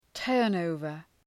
Προφορά
{‘tɜ:rn,əʋvər}